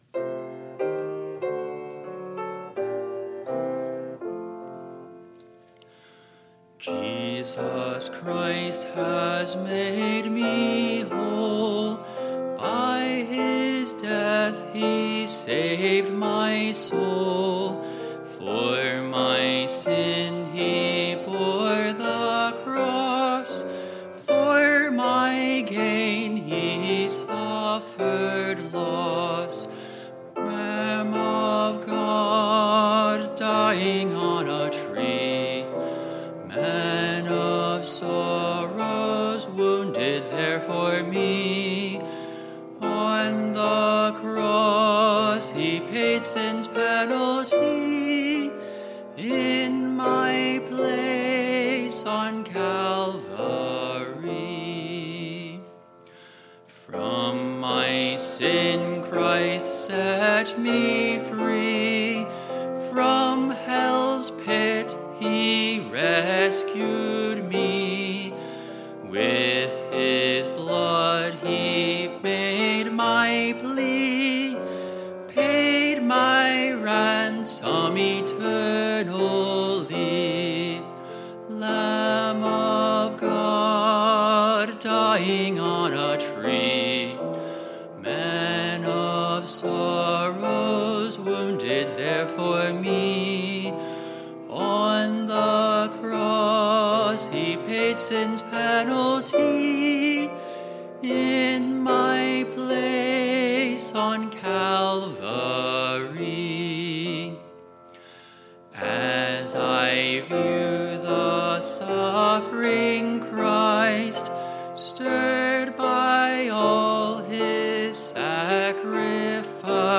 2024 Music Programs
2024 Resurrection Service